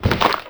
fs_zombi1.wav